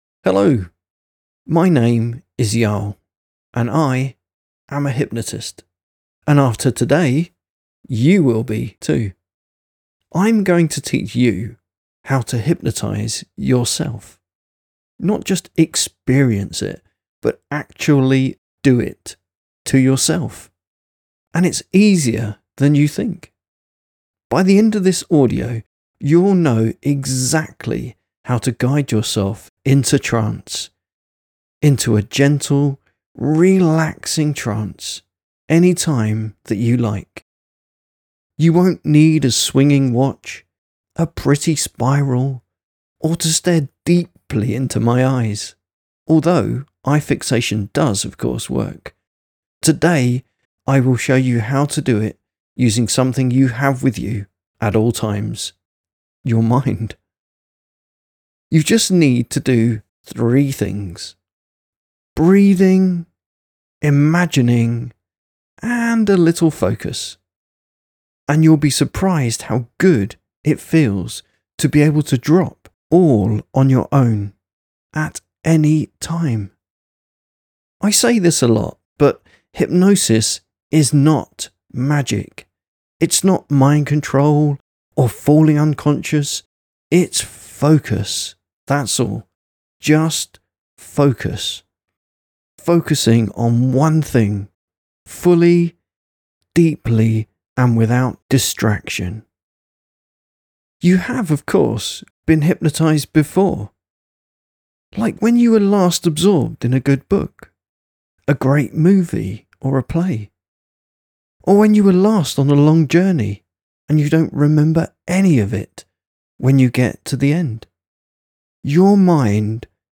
In this file, I will guide you step-by-step through the simple art of self-hypnosis. Using nothing more than your breath, your focus, and a single imagined word, you will discover how to drop into a soft, calm, soothing trance state, all on your own, anytime you like.